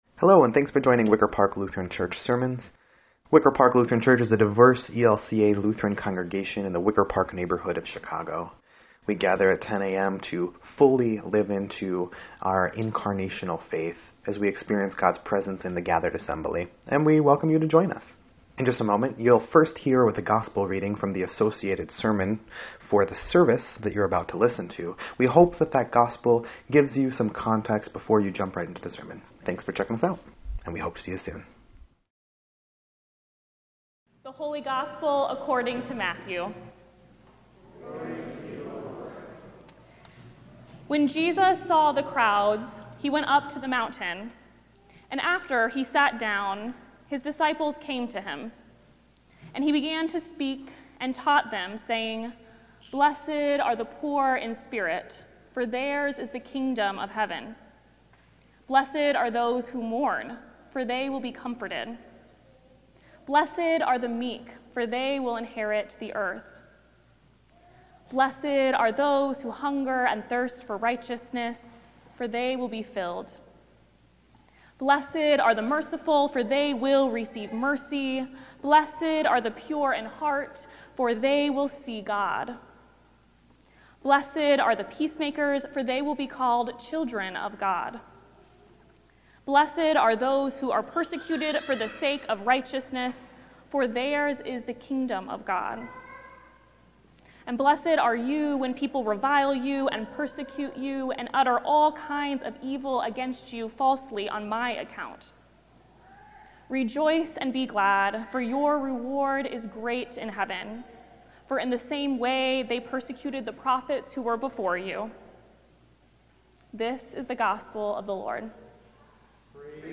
2.1.26-Sermon_EDIT.mp3